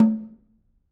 Snare2-HitNS_v3_rr1_Sum.wav